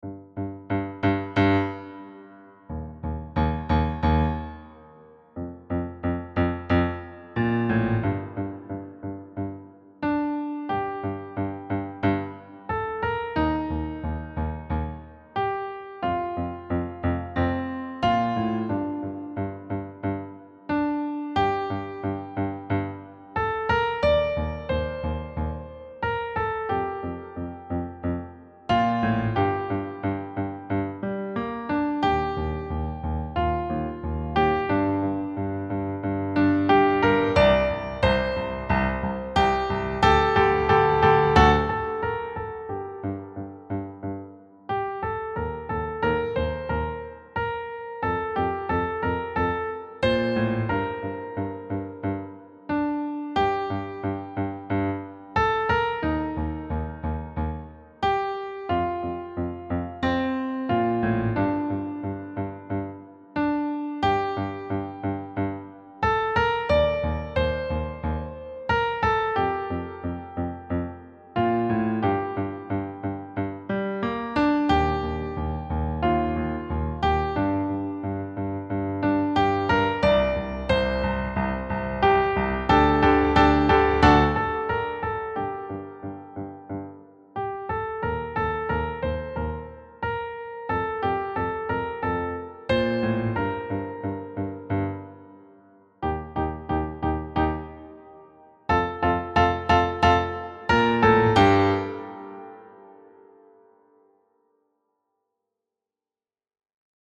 Key: G minor
Time Signature: 4/4 (BPM = 160–200)